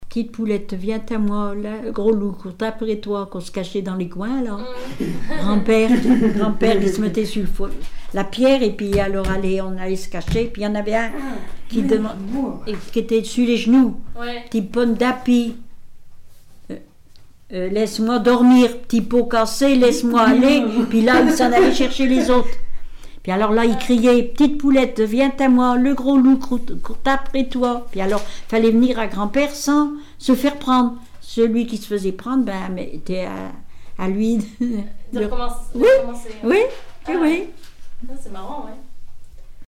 Genre brève
Témoignages sur la vie domestique
Pièce musicale inédite